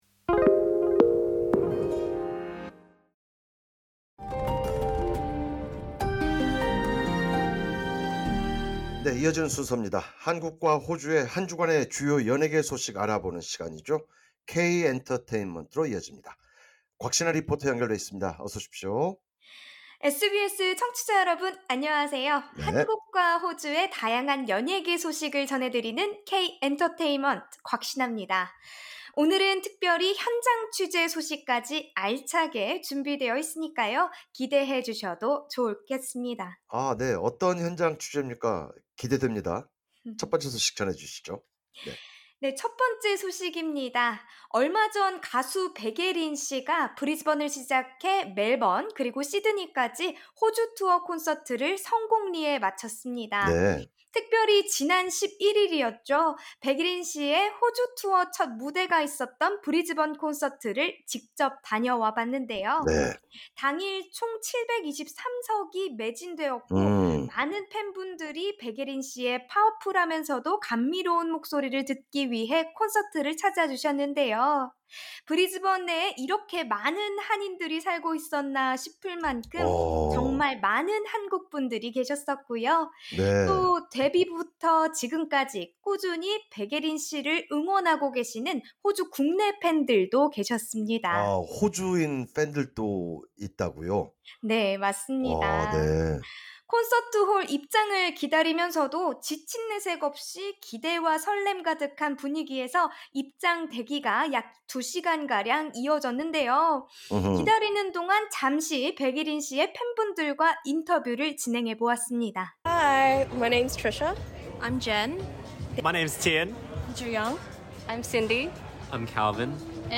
Key Points 백예린, 호주순회 공연 성황리에 마무리 오징어 게임 시즌 2 캐스팅 공개에 호주 팬들 관심 고조 진행자: 한국과 호주의 한주간의 주요 연예계 소식을 알아보는 시간이죠, 케이 엔터테인먼트로 이어집니다.